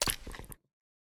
snapshot / assets / minecraft / sounds / mob / cat / eat1.ogg
eat1.ogg